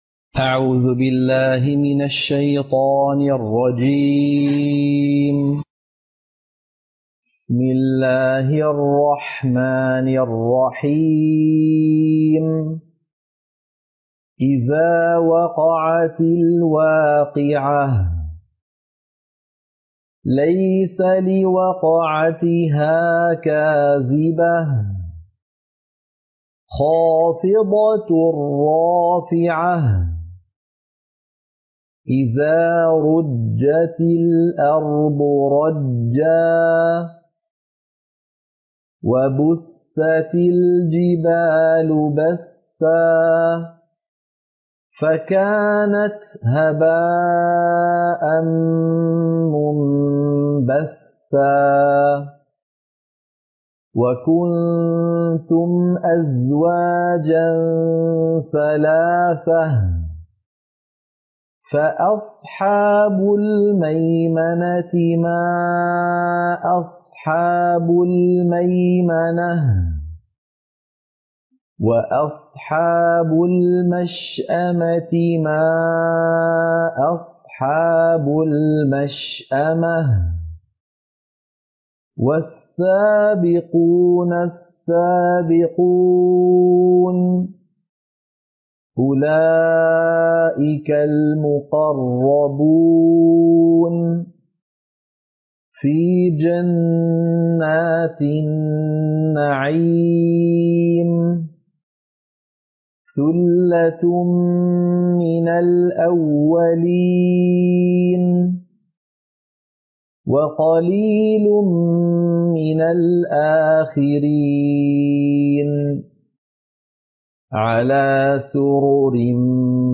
سورة الواقعة - القراءة المنهجية